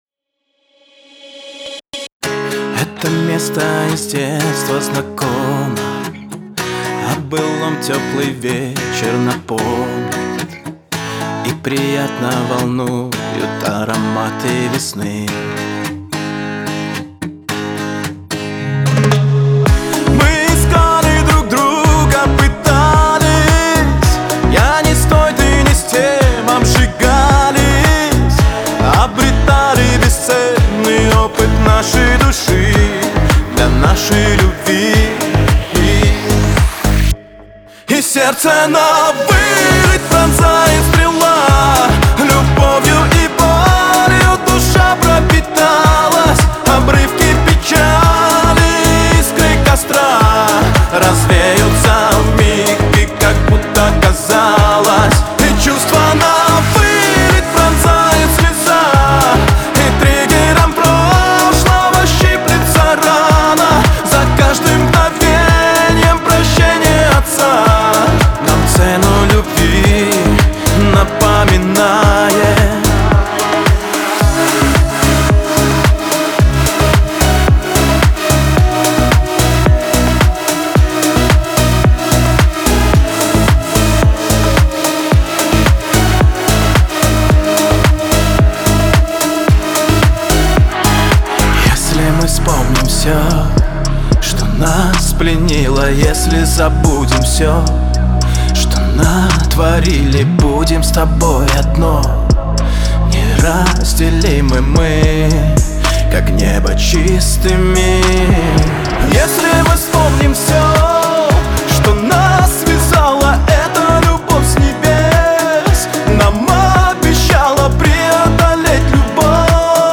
дуэт
грусть
pop